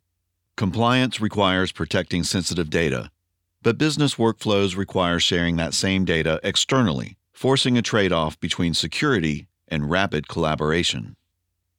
Explainer
Middle Aged